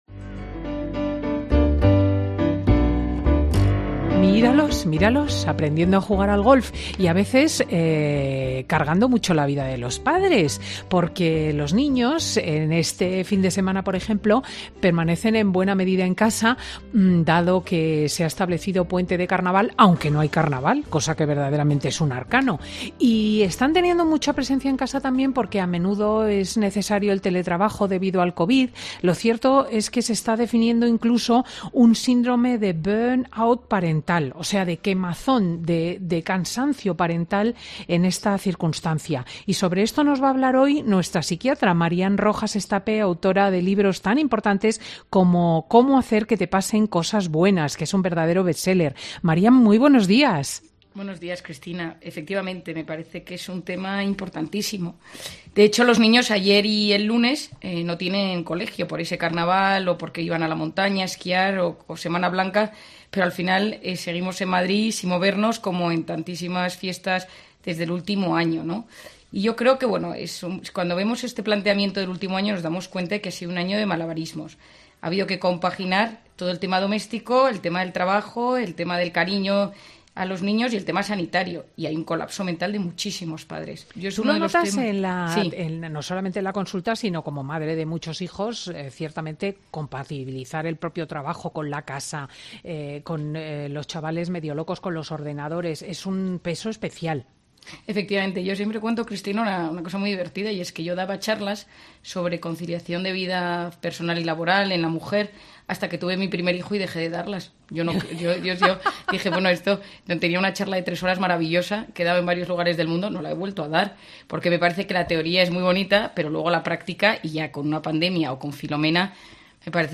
La psiquiatra Marian Rojas regresa a Fin de Semana con Cristina para dar claves para conservar la calma en la epidemia